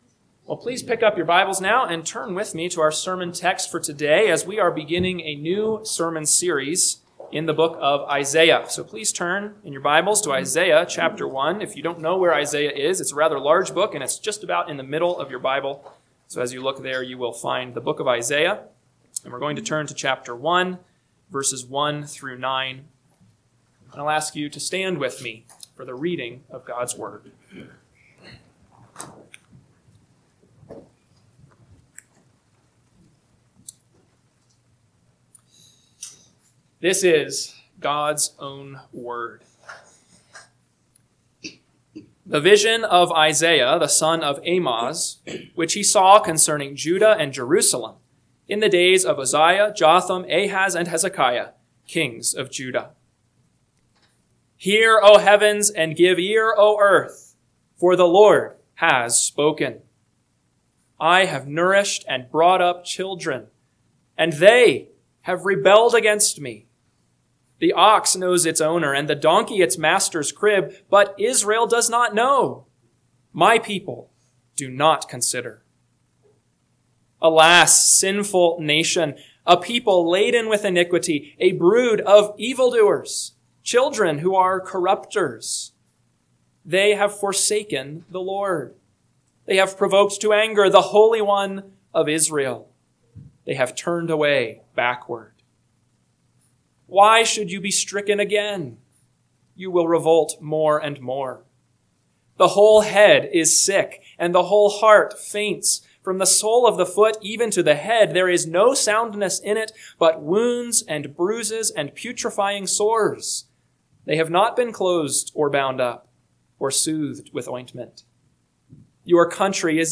AM Sermon – 9/28/2025 – Isaiah 1:1-9 – Northwoods Sermons